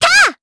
Dosarta-Vox_Attack1_jp.wav